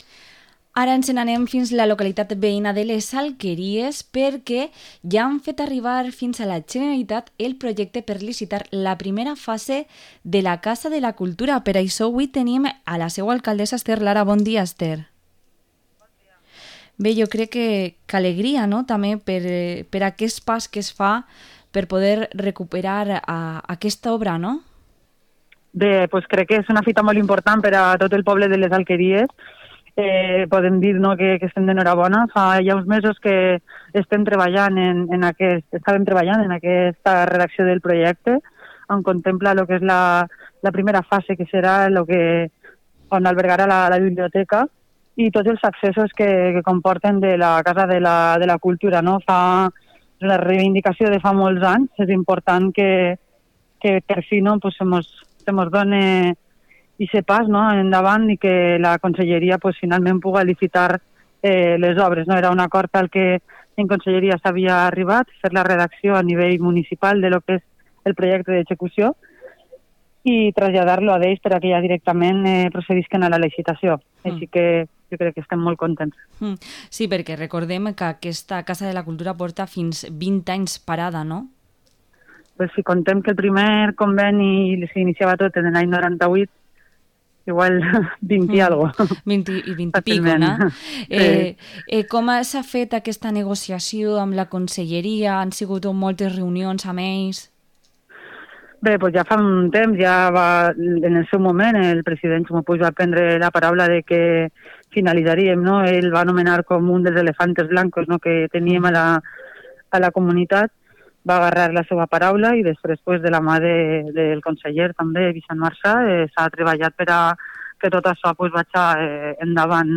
Entrevista a la alcaldesa de Les Alqueries, Esther Lara